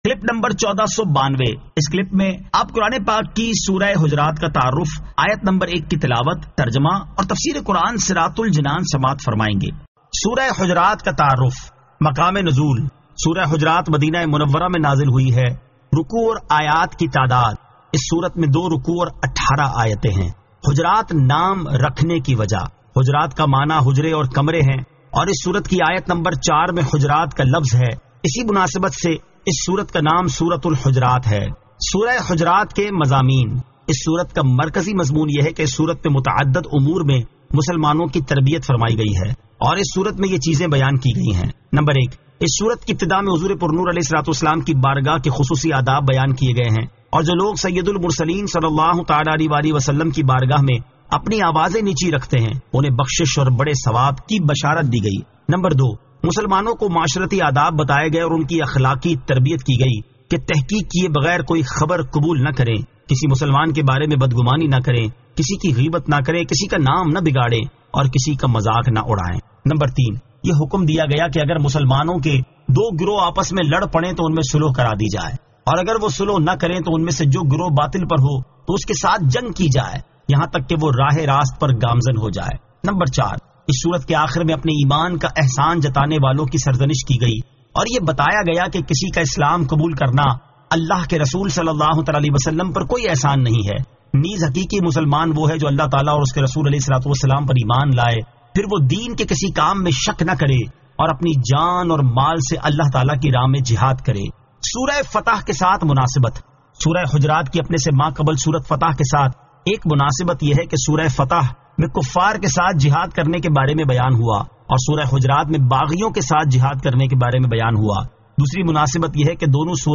Surah Al-Hujurat 01 To 01 Tilawat , Tarjama , Tafseer